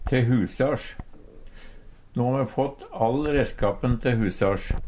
te husars - Numedalsmål (en-US)